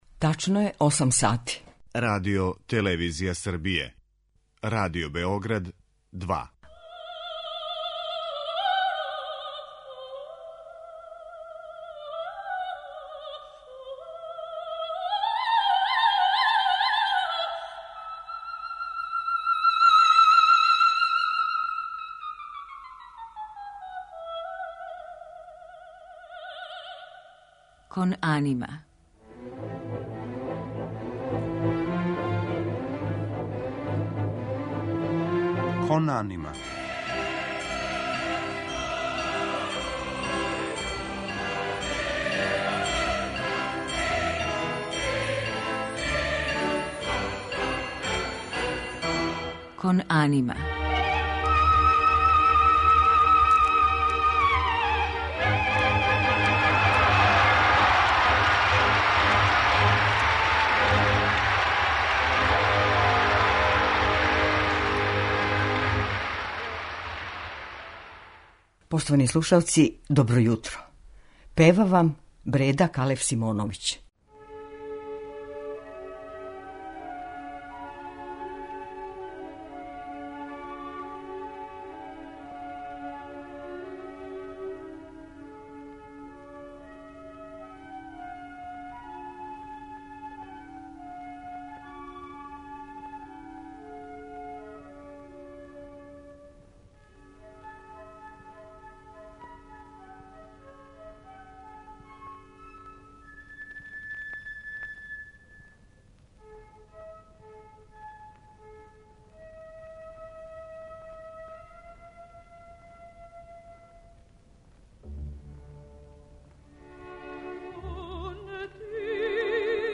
У музичком делу емитоваћемо арије из опера у којима је наступала наша данашња гошћа.